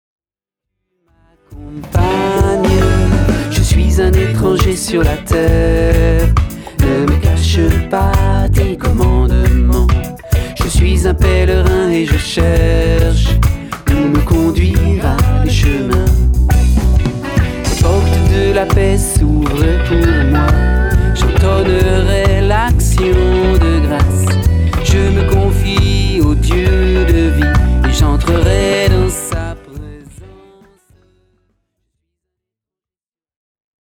Du rythme et du souffle pour nous élever vers le Père.
Pour jeunes et moins jeunes, un disque qui bouge !